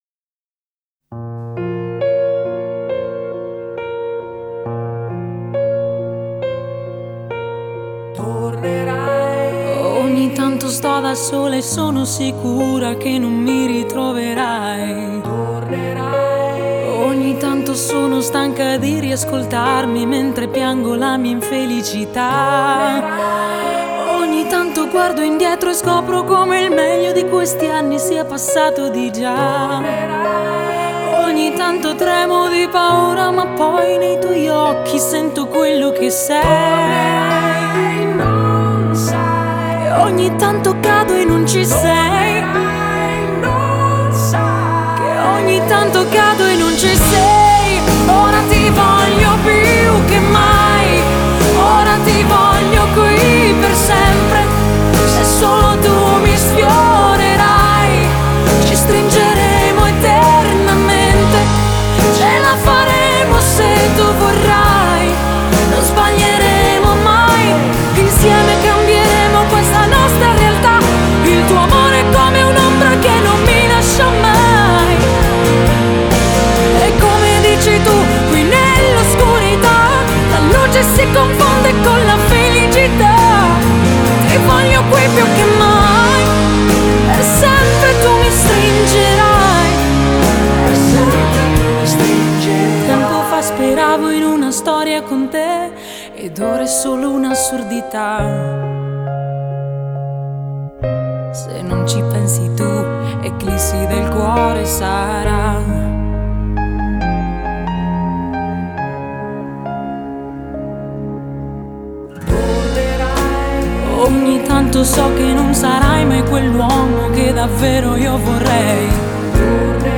Genre: Pop, Pop Rock